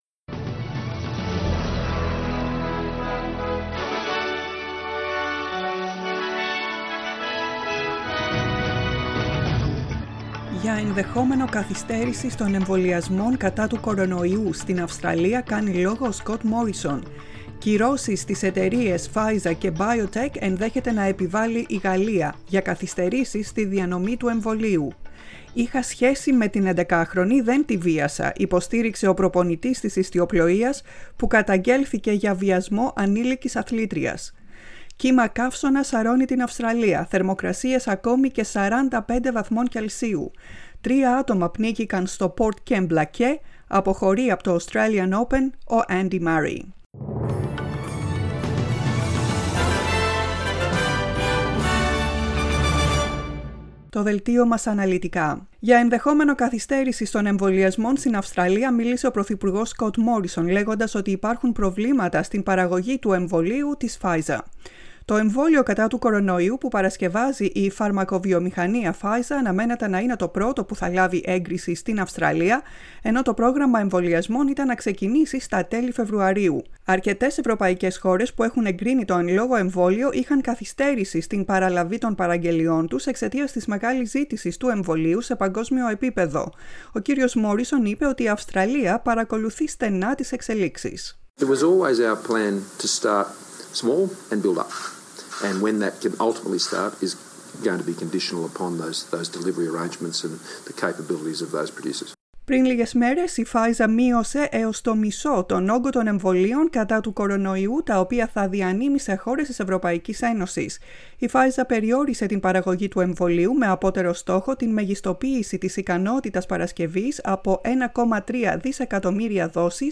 The main bulletin of the Greek Language Program